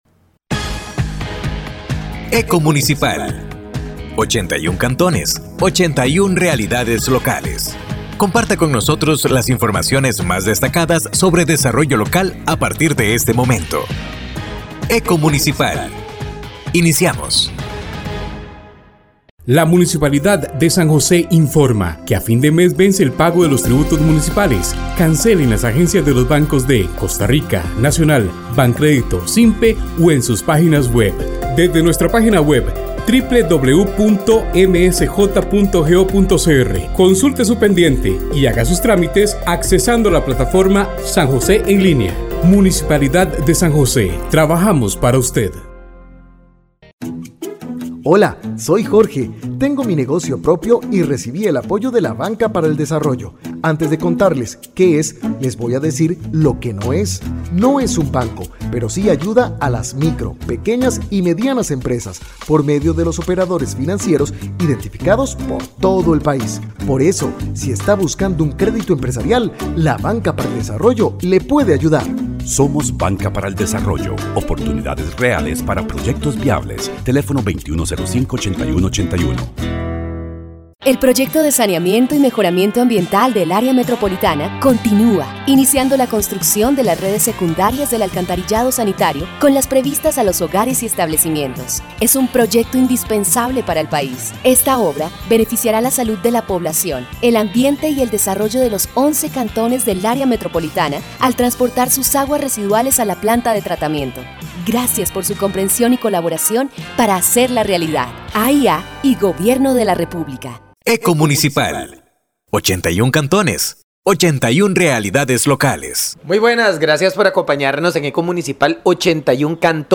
Programa de Radio Eco Municipal